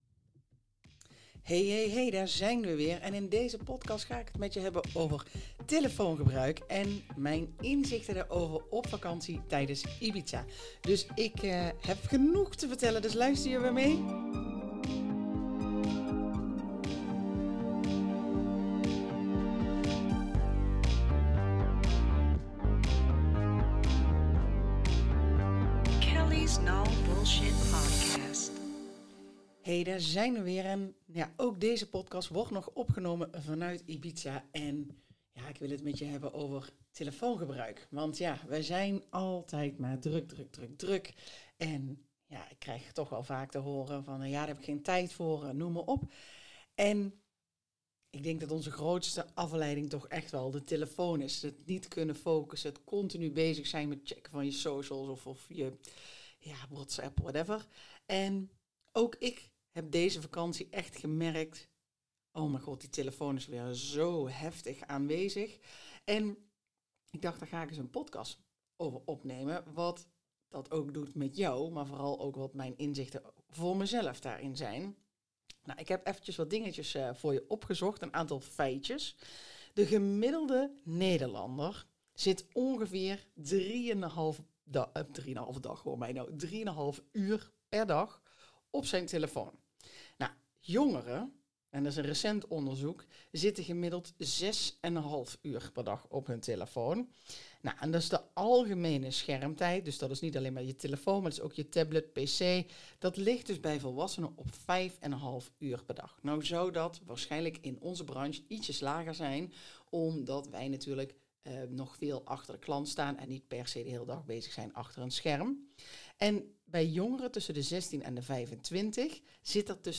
Ik zit op Ibiza, met het geluid van de zee op de achtergrond… en mijn telefoon in m’n hand.